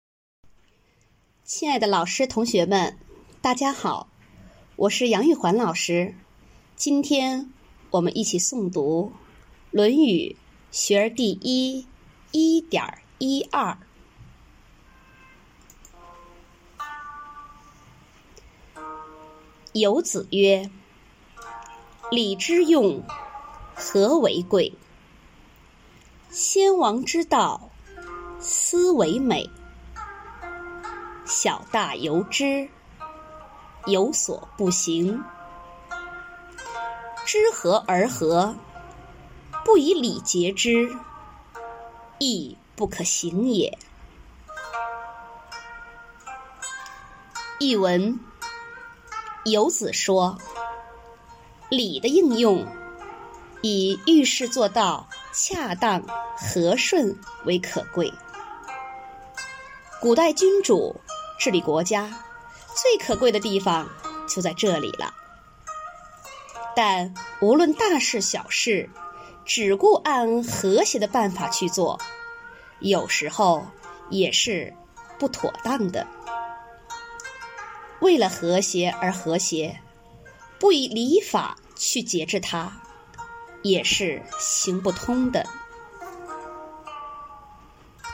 每日一诵0303.mp3